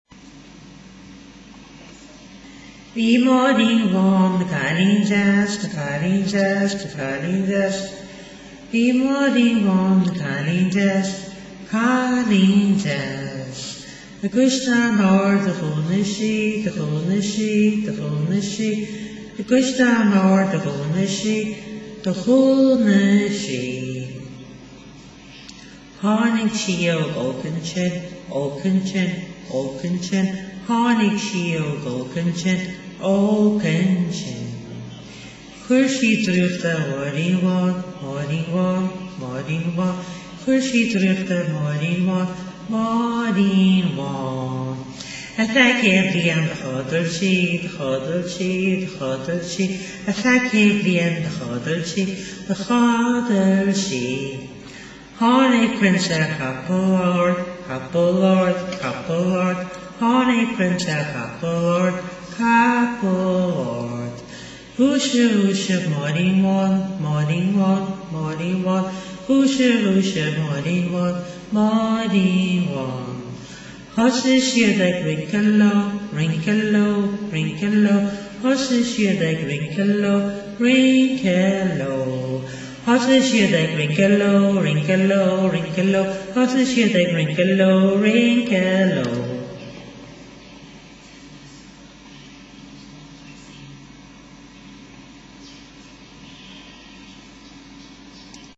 The last verse is sung faster